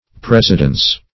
Presidence \Pres"i*dence\, n.
presidence.mp3